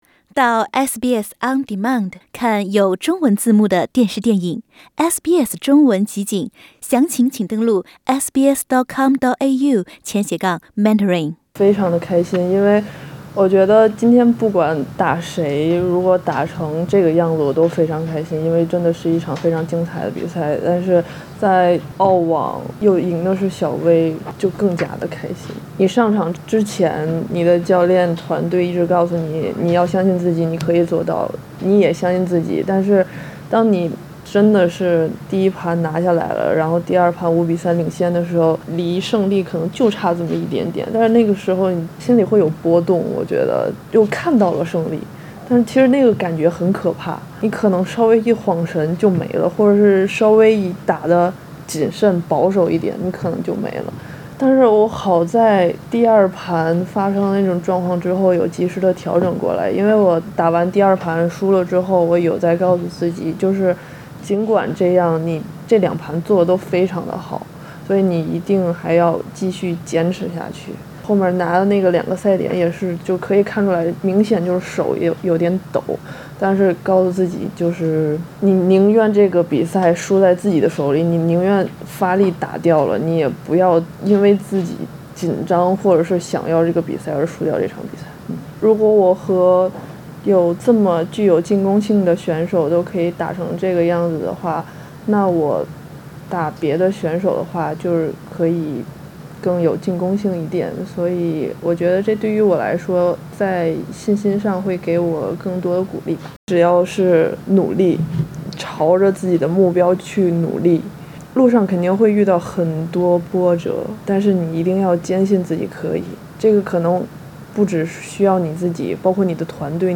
点击图片音频，收听王蔷的完整采访。